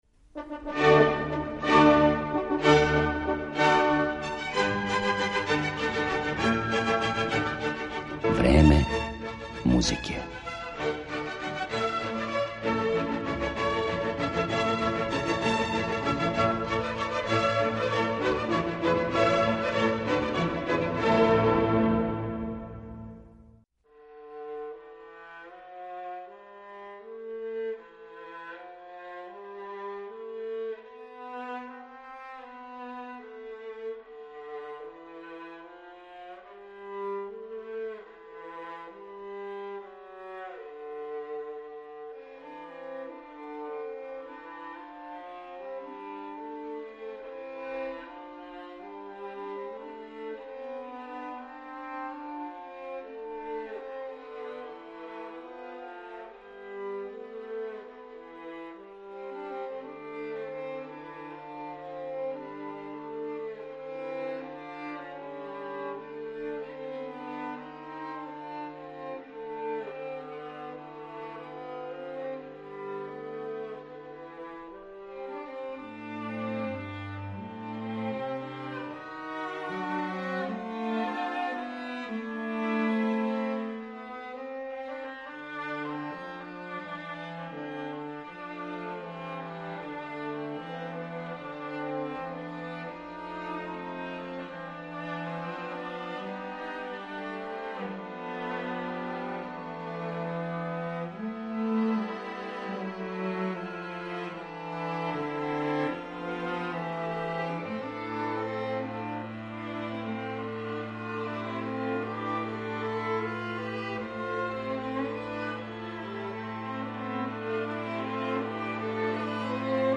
Гошће емисије Време музике биће чланице гудачког квартета „ТАЈЈ”.